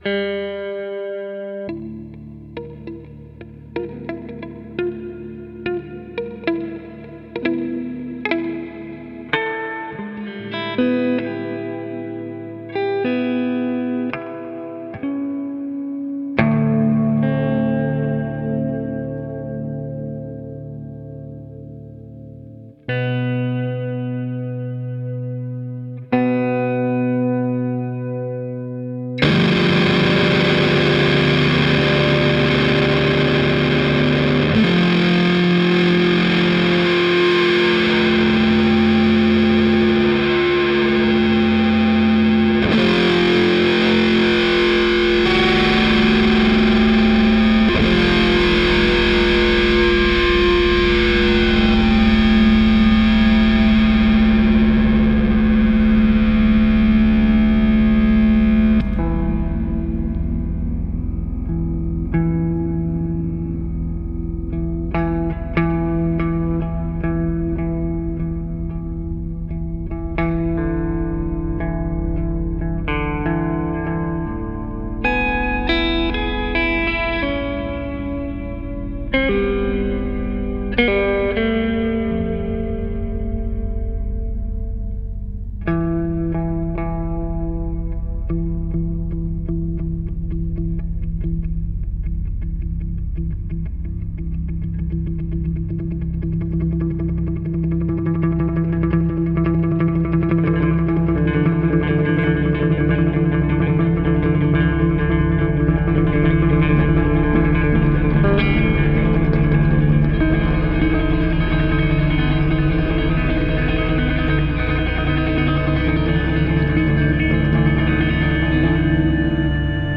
electrified baritone saxophone
electric guitar
analog synthesizer
electric bass
drums, acoustic and electronic percussion sets
Recorded in Moulins-sur-Ouanne, France, summer 2019.